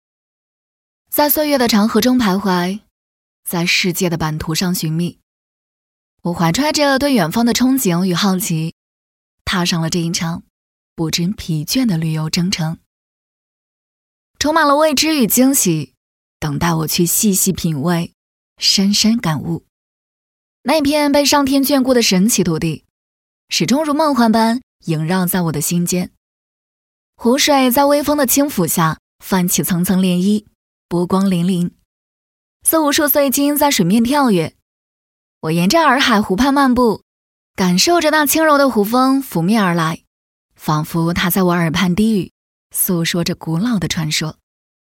Warm and Magnetic_Sourse.mp3